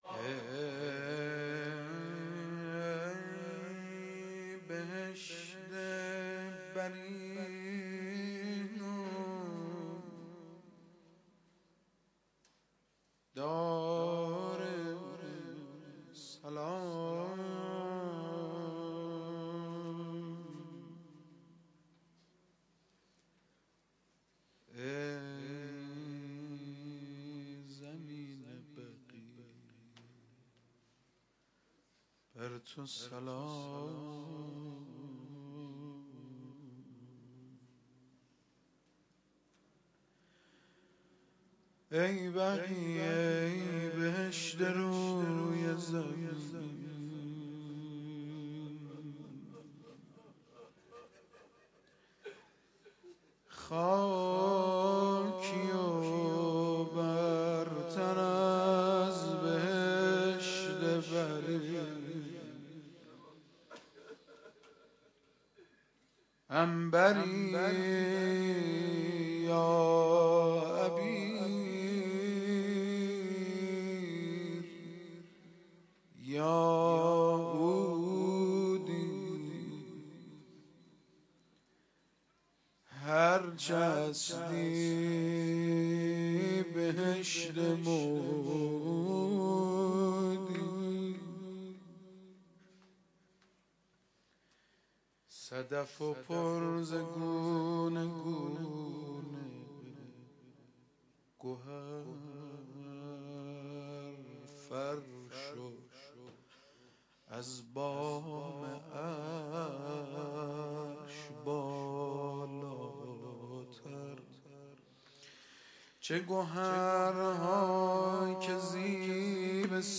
روضه و توسل جانسوز ویژه سالروز تخریب بقیع